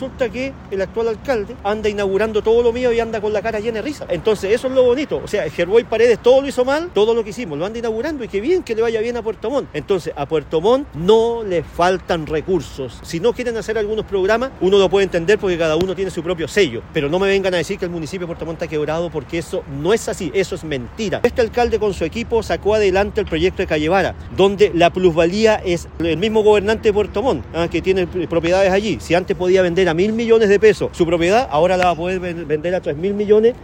Salió del lugar, esquivo en primera instancia a la prensa, con quien finalmente conversó por más de 15 minutos, ininterrumpidamente.